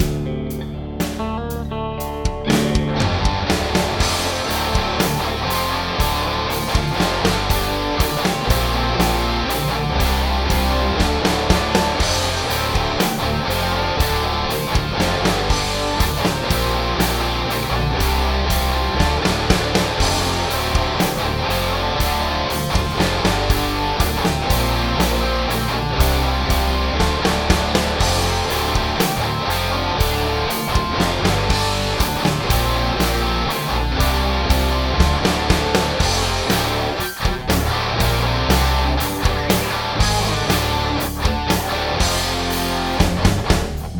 Just to contrast the last one, this is one that's loud and distorted:
I went back to working on a riff I was struggling with - just couldn't get it to sound big and heavy - my usual technique of adding another riff over it, wide panning it and sitting it back a bit worked: View attachment 97102 I think I'm getting the sound I want on this one finally although any tips to improve it would be gratefully recieved.